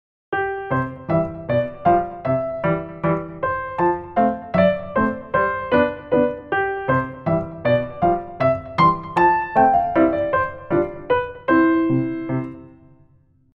コロコロと変わる和音と２拍子の軽い感じがピッタリ。